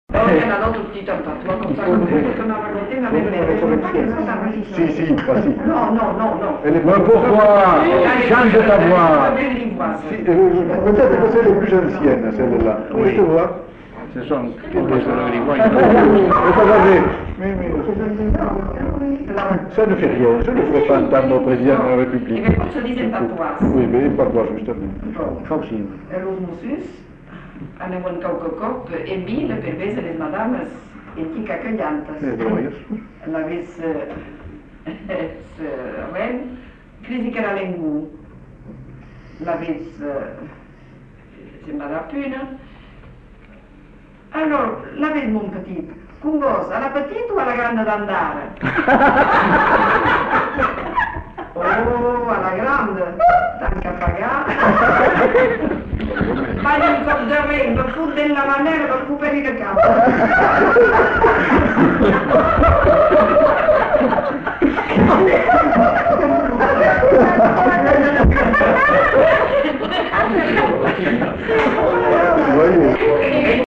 Un récit en gascon
Aire culturelle : Bazadais
Lieu : Uzeste
Genre : conte-légende-récit
Effectif : 1
Type de voix : voix de femme
Production du son : parlé